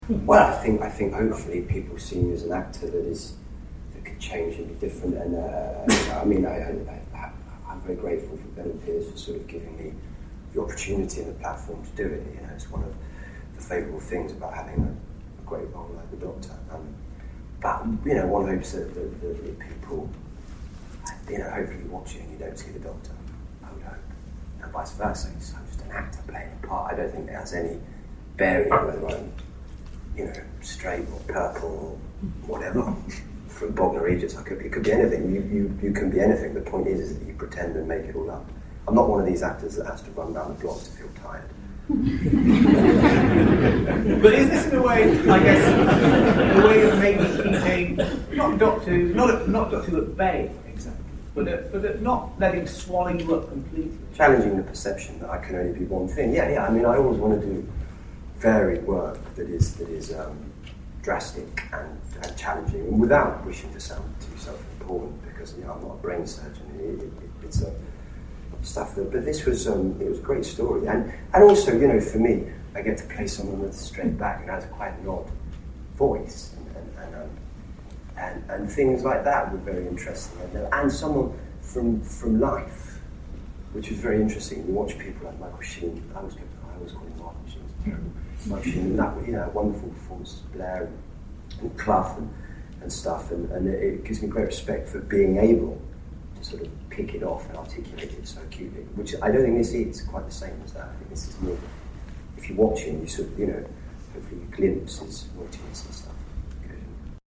There was a Q&A after the screening with Matt, writer Kevin Elyot and director Geoffrey Sax.
Below are a few short audio extracts of what Matt had to say: